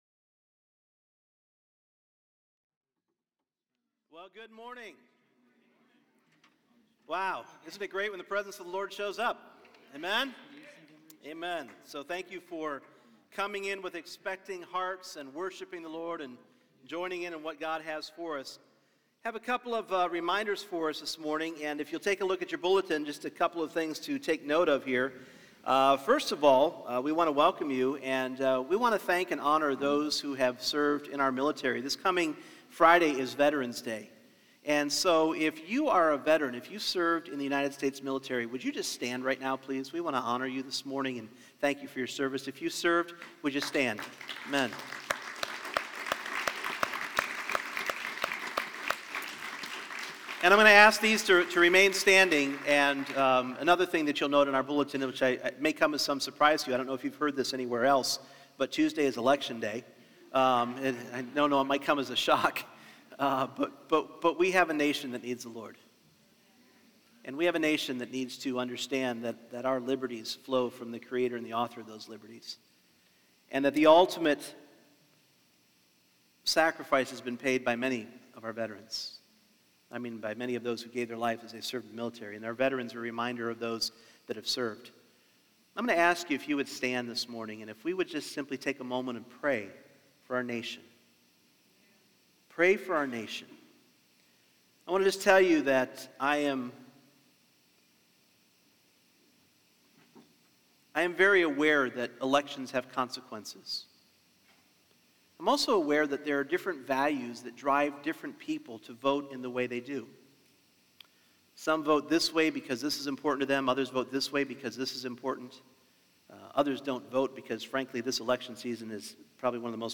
Individual Messages Service Type: Sunday Morning There is a remnant of those who will hear the Lord's words to give us a "new heart" -- a heart of flesh and not of stone.